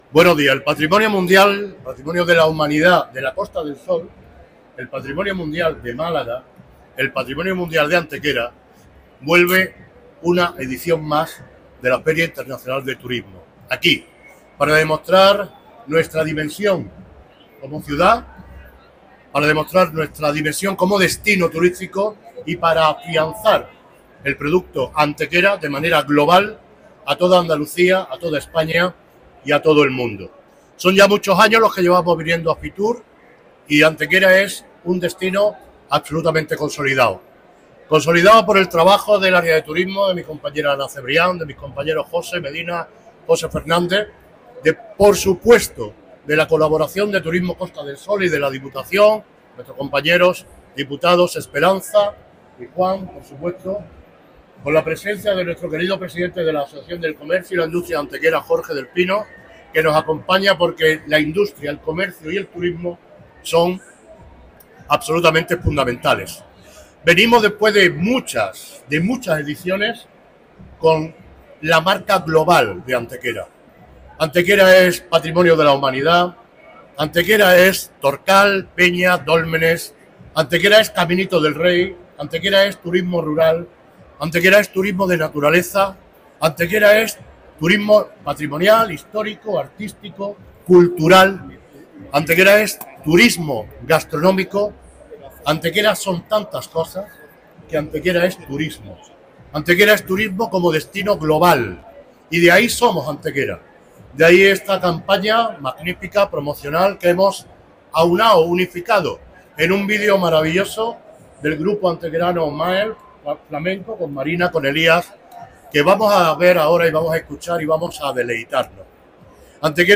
El alcalde de Antequera, Manolo Barón, ha presidido hoy en Madrid la presentación promocional de la ciudad como destino turístico en el marco del desarrollo de FITUR 2025, destacando su proyección como Patrimonio Mundial de la UNESCO y su compromiso con el turismo cultural, natural y gastronómico.
Cortes de voz